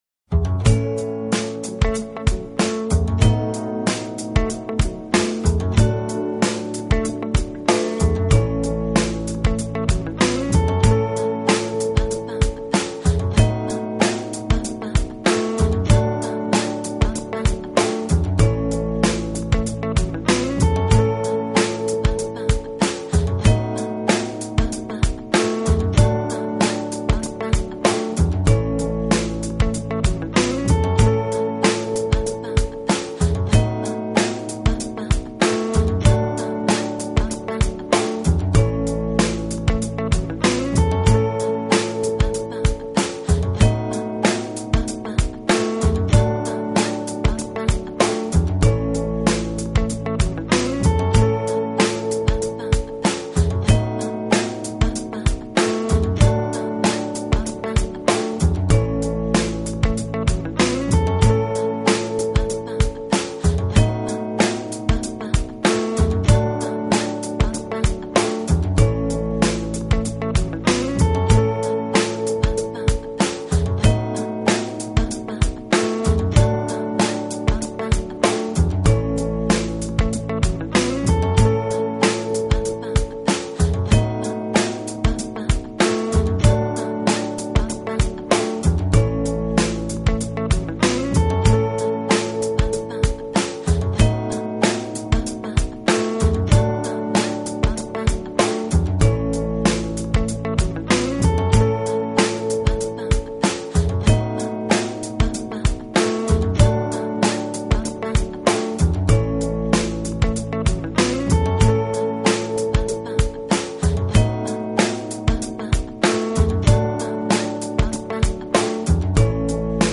【轻音爵士】
包括人声、弦乐、管乐与打击乐器群；他们展现出的乐风是多元
其音乐风格揉合爵士乐、古典乐及古巴音乐，让乐